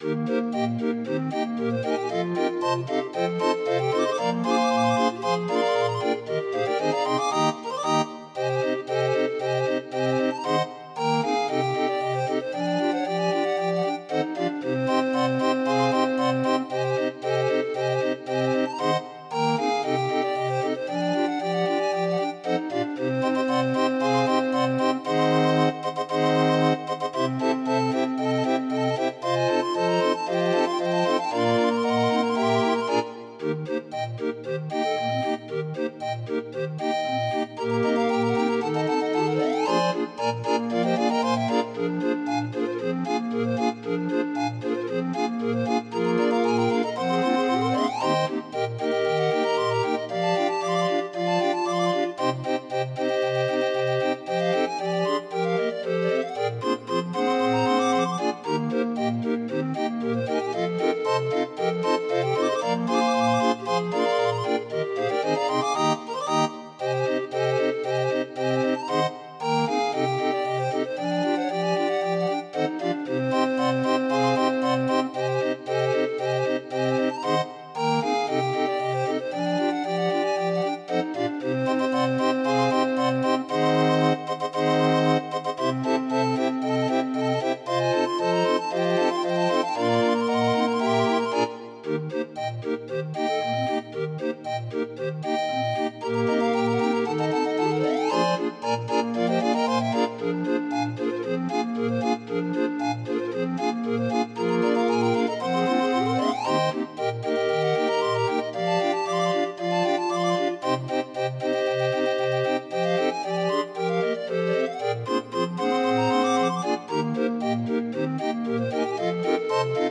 Demo of 31 note MIDI file